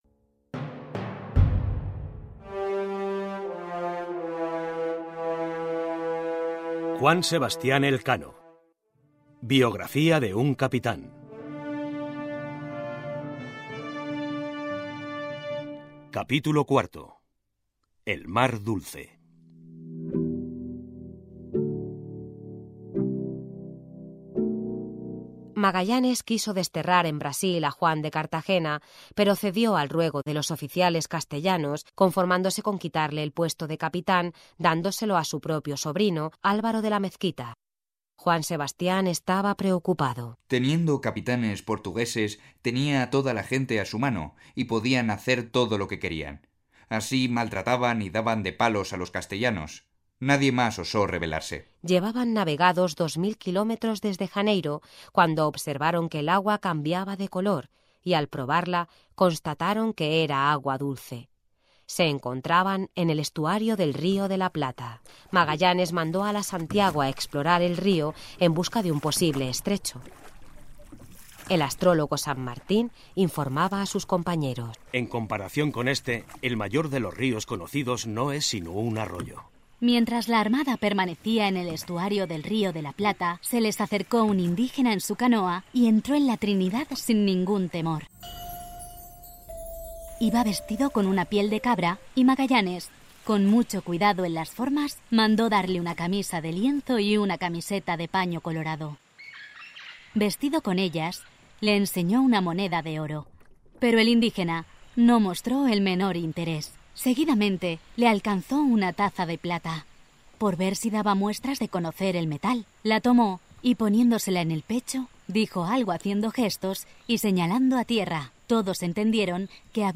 Audiolibro: Elkano biografía de un cápitan capítulo 4